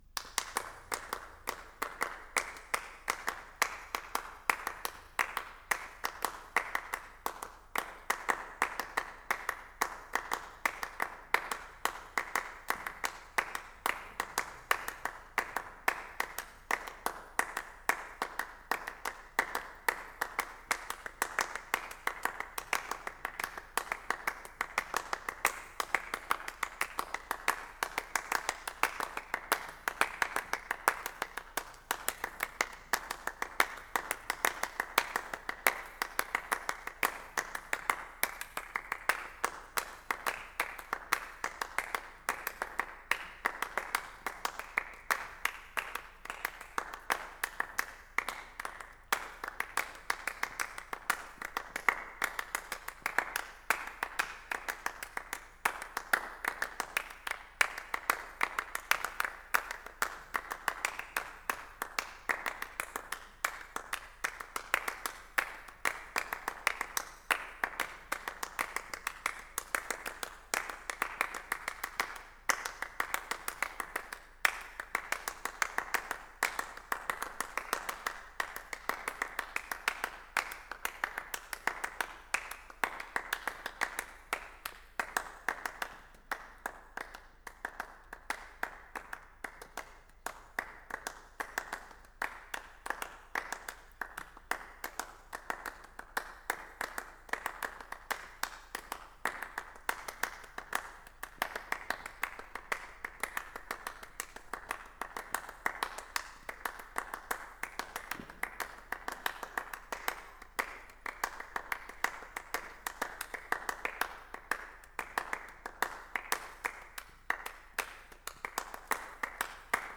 Forte Interrotto – Camporovere, 11 agosto 2020.
Genere: Classical.